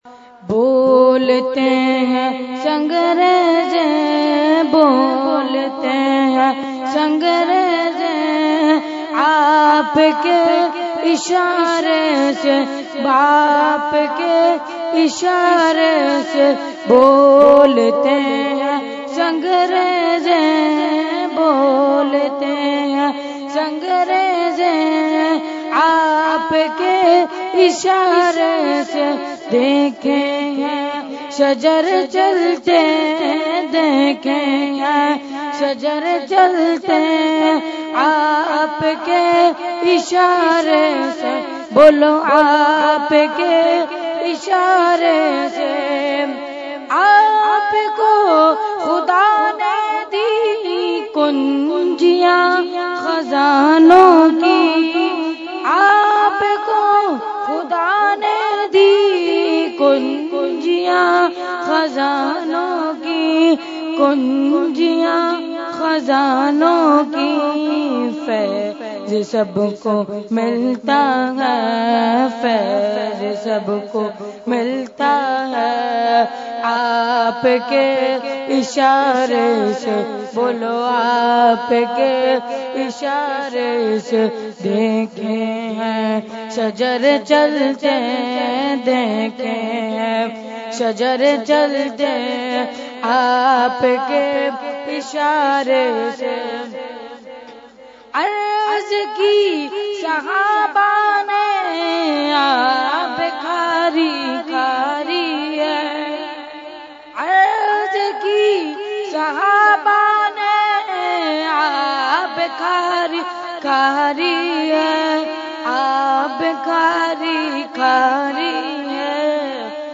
Category : Naat | Language : UrduEvent : Urs Makhdoome Samnani 2018